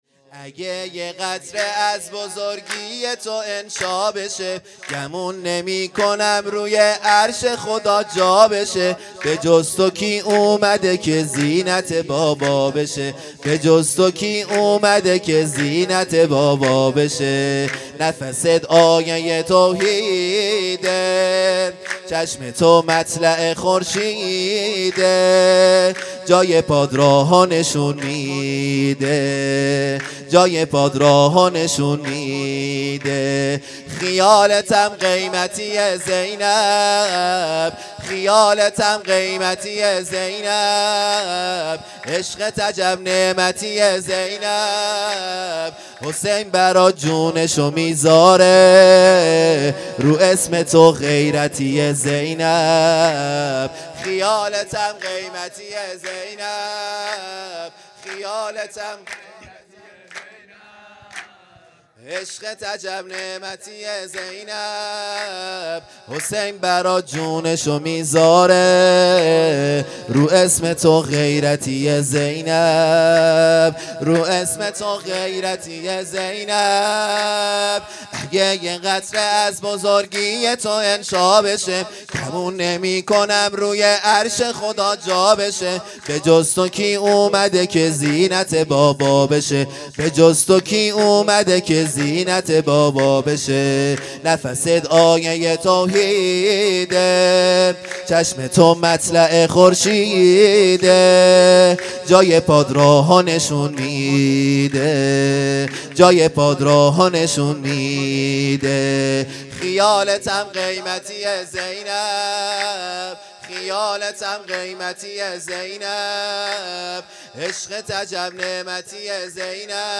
خیمه گاه - هیئت بچه های فاطمه (س) - سرود | اگه یه قطره از بزرگی تو انشا بشه
ویژه برنامه ولادت حضرت زینب(س)